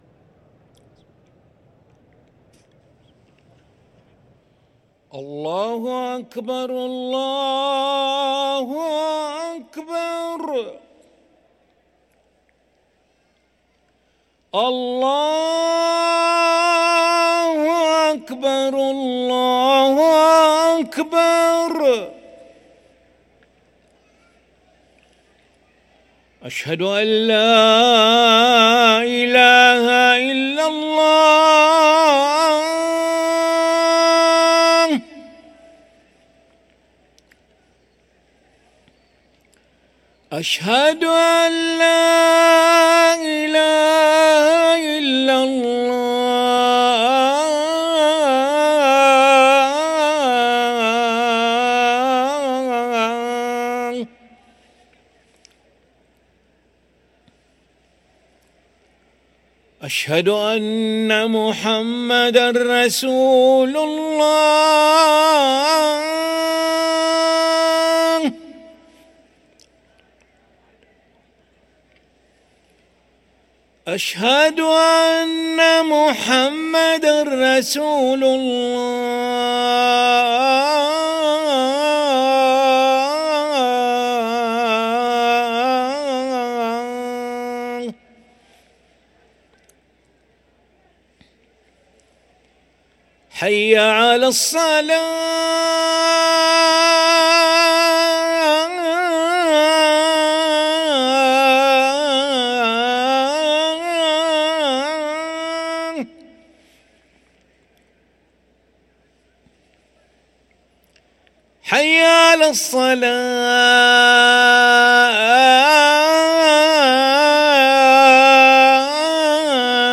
أذان العشاء للمؤذن علي ملا الخميس 15 صفر 1445هـ > ١٤٤٥ 🕋 > ركن الأذان 🕋 > المزيد - تلاوات الحرمين